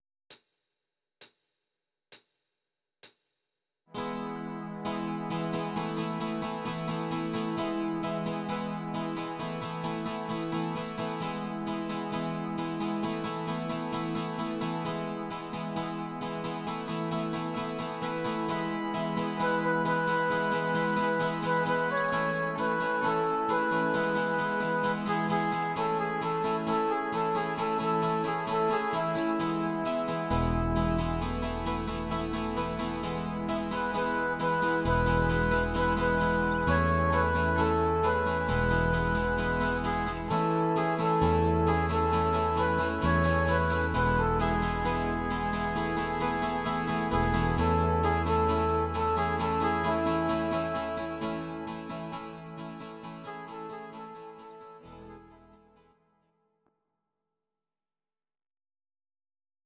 Audio Recordings based on Midi-files
Pop, Rock, 1970s